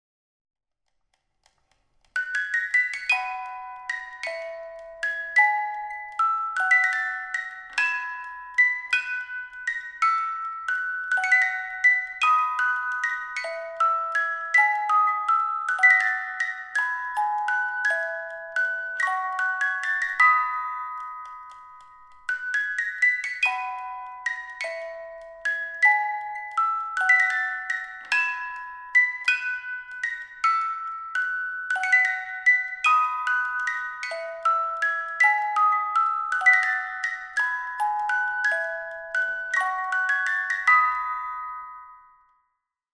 von Spieluhr | MERZ Beruhigungsmusik
spieluhr.mp3